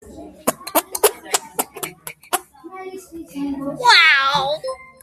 Sound Effects
Fart Power